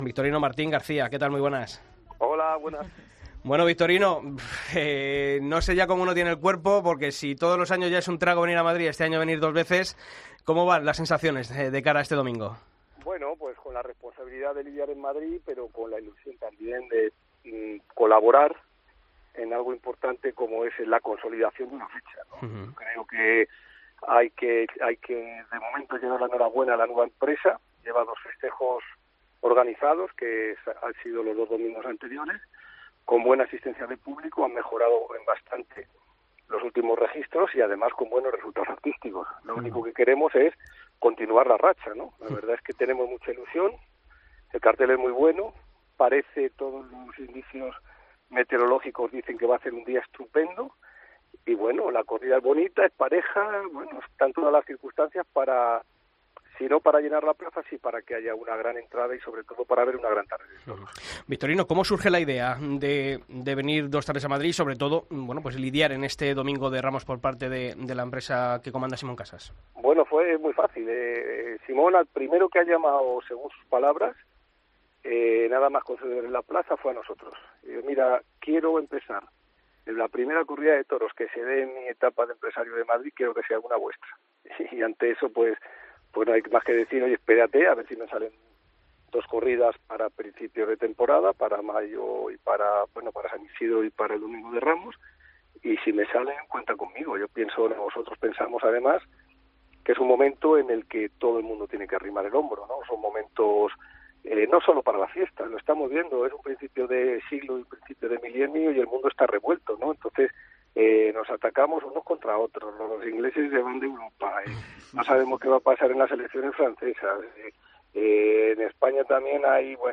Escucha la entrevista a Victorino Martín en El Albero